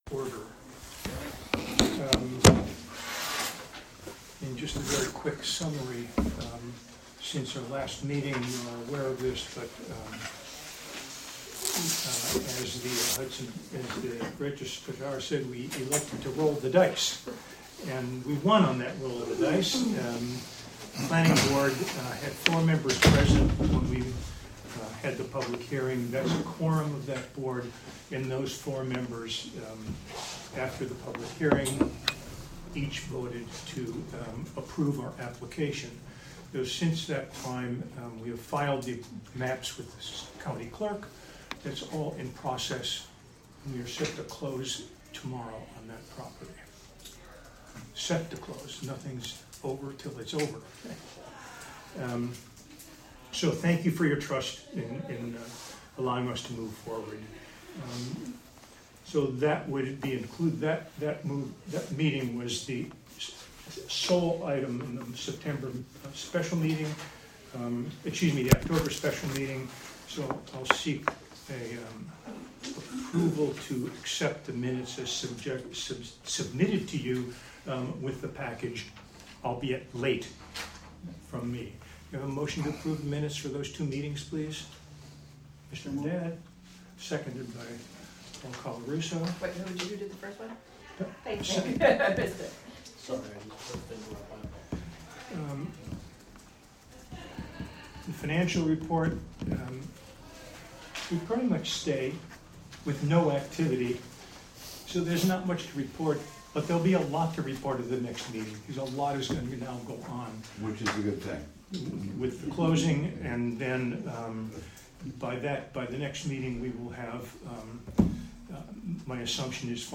Special Meeting of the Common Council will be held...